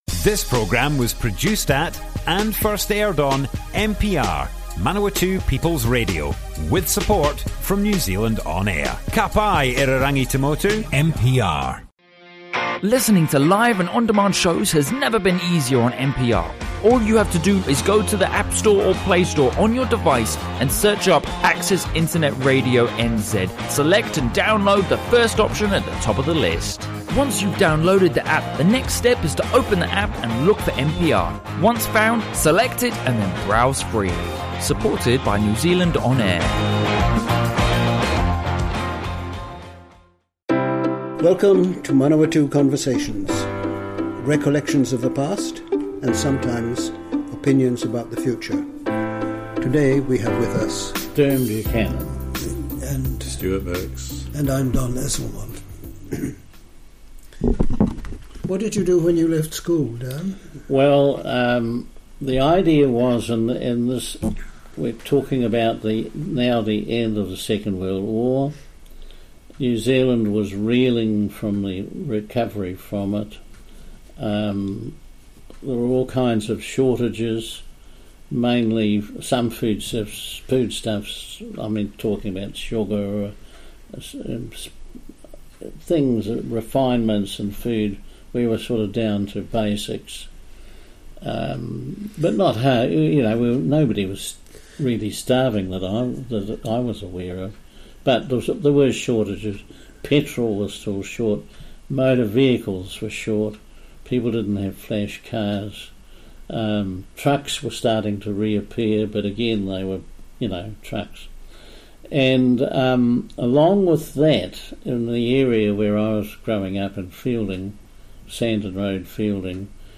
Broadcast on Manawatū People's Radio, 20th August 2019.